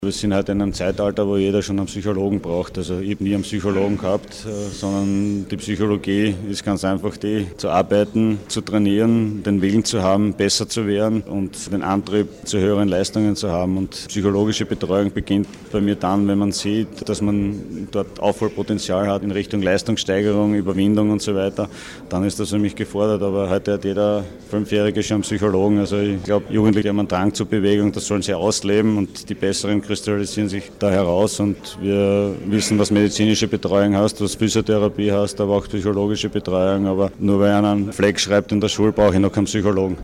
Statements
Fragen an Thomas Muster: